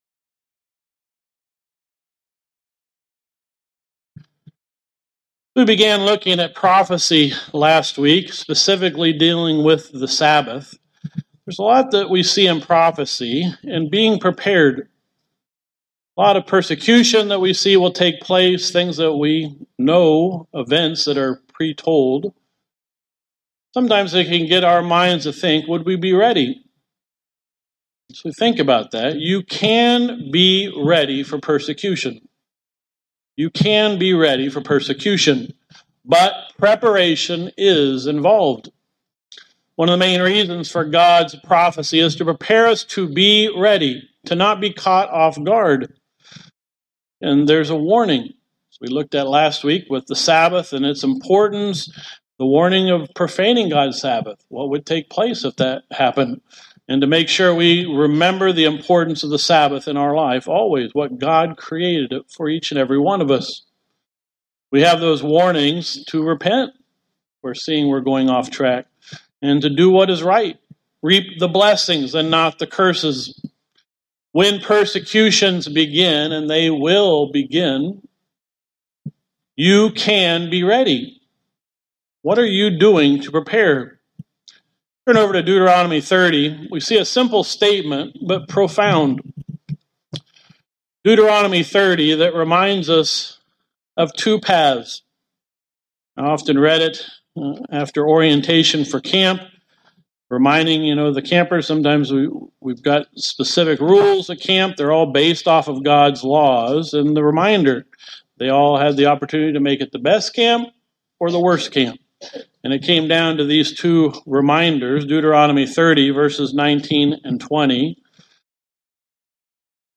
This sermon looks at three individuals to learn how we can prepare.